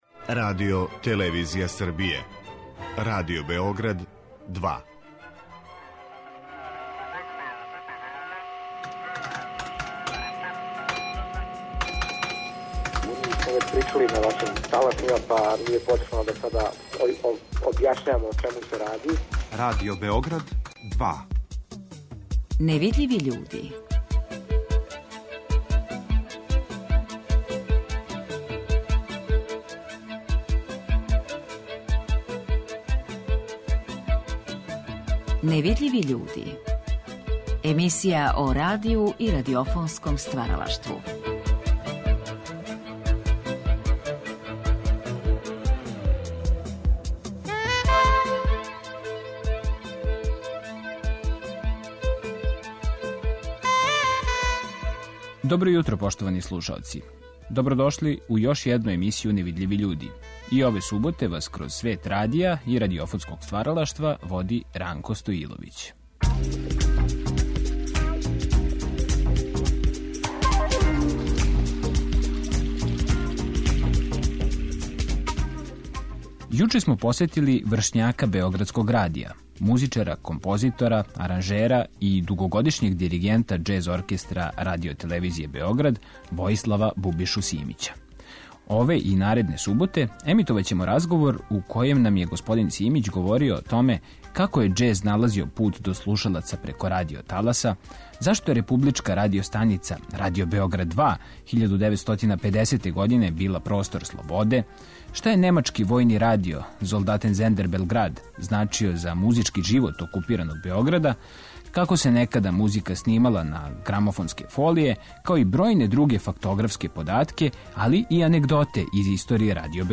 Енрико Јосиф је говорио у циклусу емисија „Гост Другог програма" 1985. године.